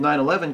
Ääntäminen
Vaihtoehtoiset kirjoitusmuodot nine-eleven Ääntäminen US Tuntematon aksentti: IPA : /naɪn.əˈlɛ.vən/ Haettu sana löytyi näillä lähdekielillä: englanti Kieli Käännökset espanja 11-S ranska 11-Septembre saksa 11.